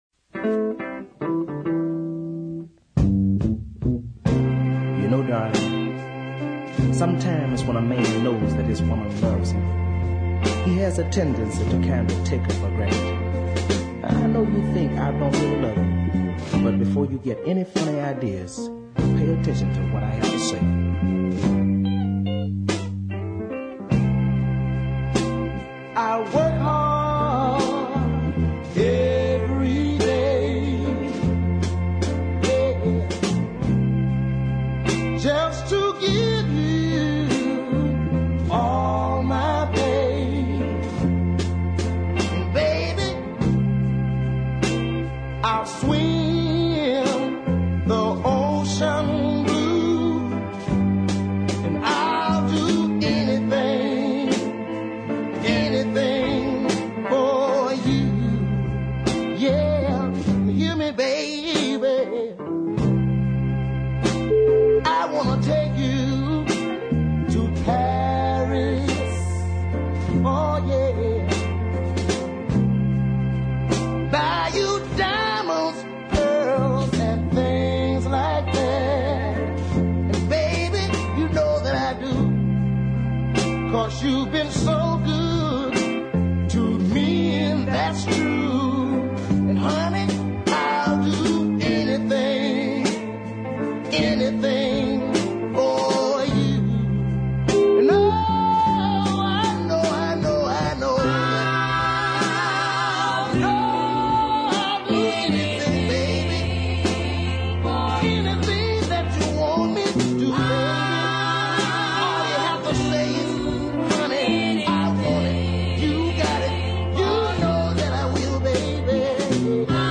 tough hard hitting Chi-town music.
down beat